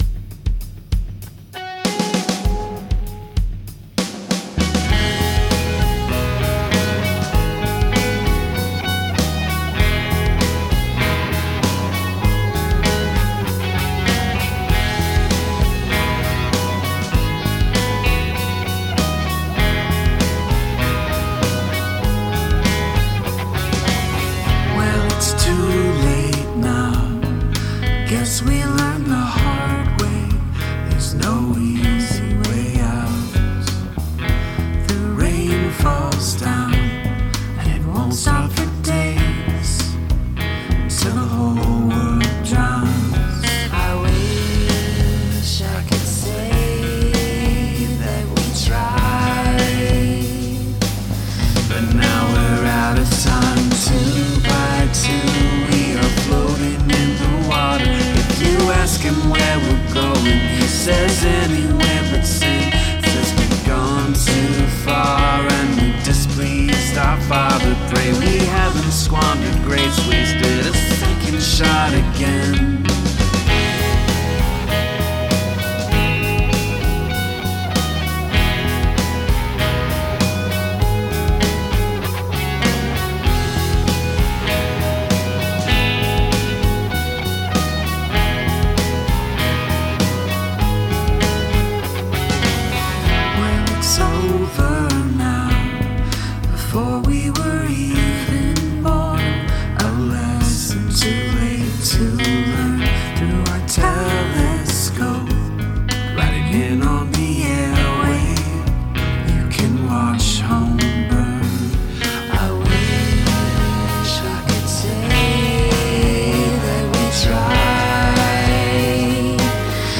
Vocals are pretty throughout.